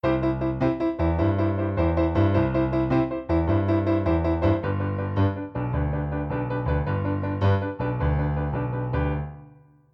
Here are two bars of C and two bars of G so you can see what this triplet right hand and boogie left hand combo looks like –